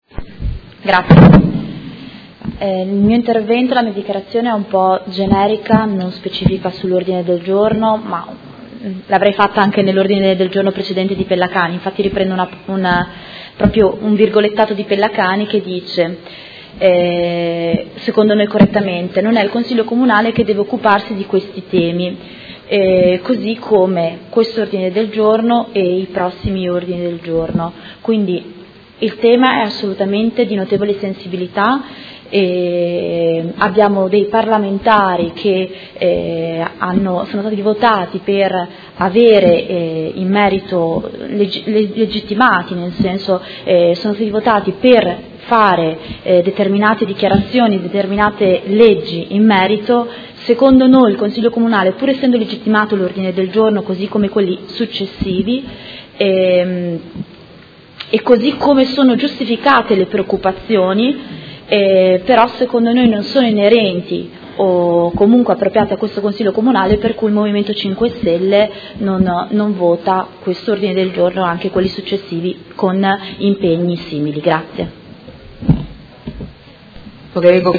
Seduta del 9/11/2017. Dibattito su Ordine del Giorno del Gruppo Forza Italia avente per oggetto: L’aumento costante degli arrivi di migranti richiede politiche chiare e decisive per limitare e regolamentare il flusso e interrompere la catena sempre più lunga dei morti in mare